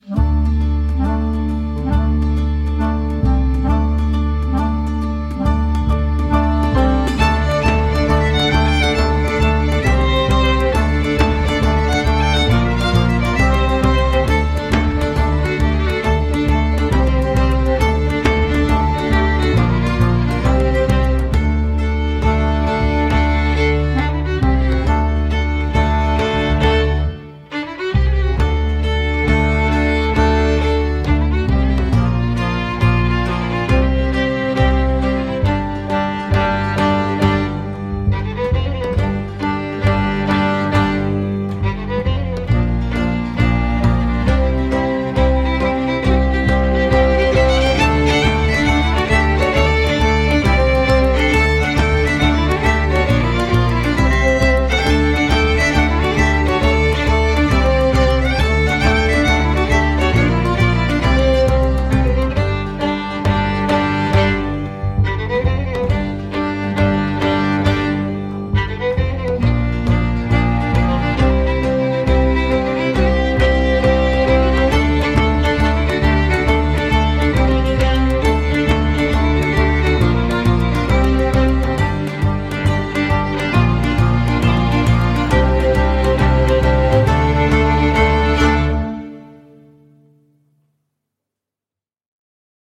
Un joli score avec un joli thème principal décliné à l’envi.